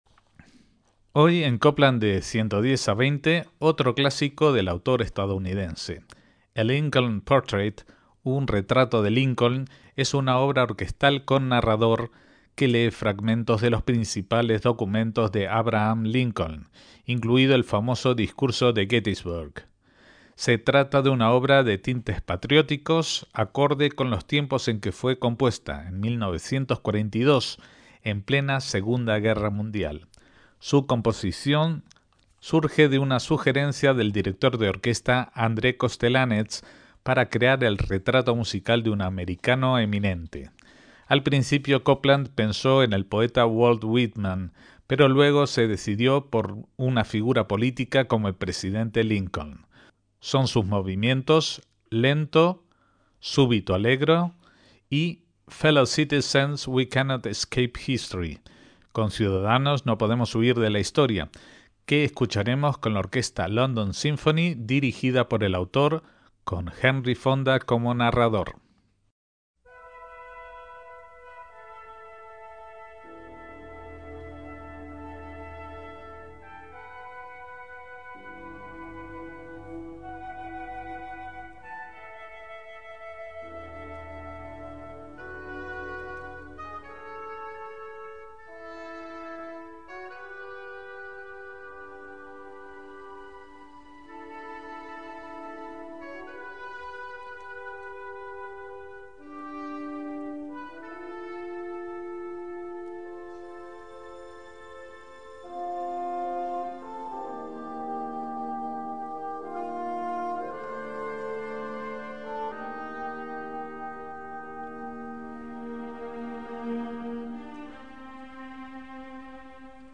una obra para orquesta y narrador
Entre sus melodías suenan algunas canciones populares de la época.